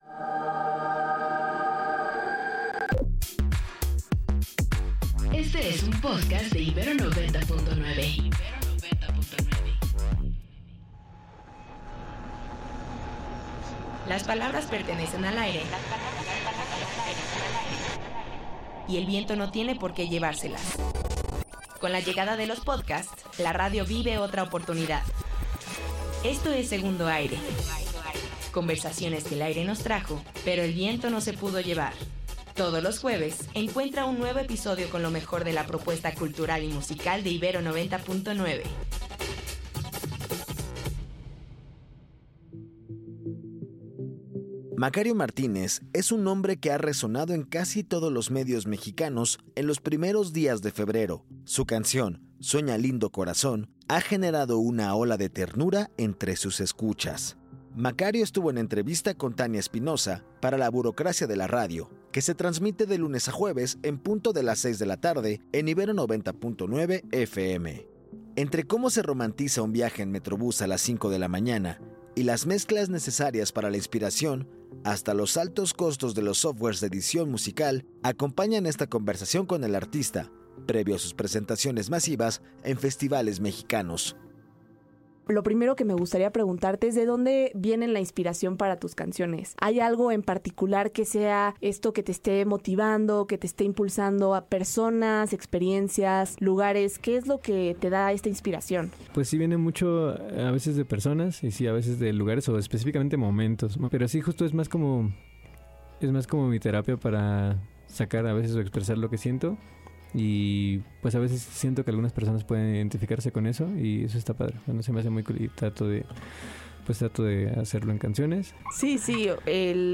Sueña Lindo, entrevista